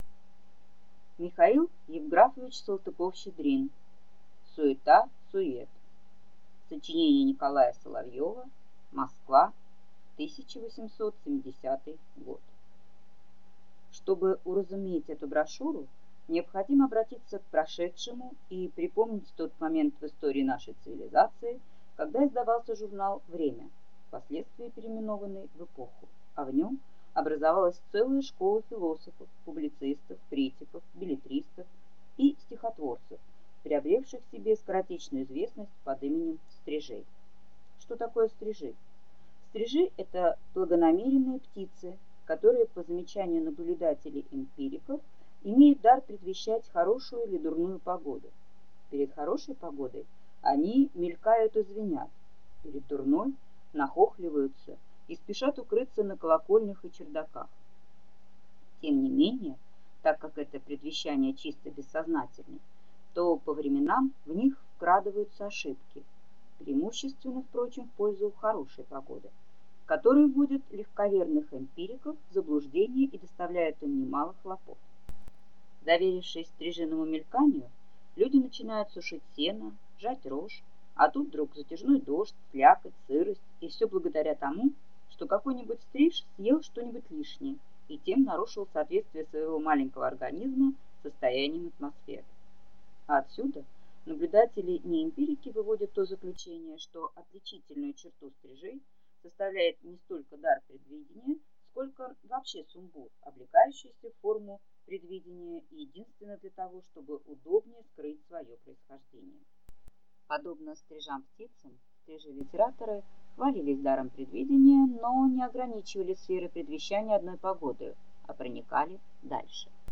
Аудиокнига Суета сует | Библиотека аудиокниг